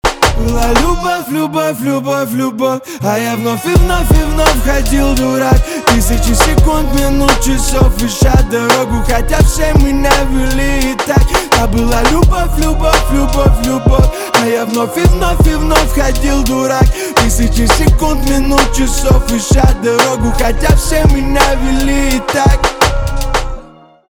русский рэп , битовые , басы
грустные , чувственные , пианино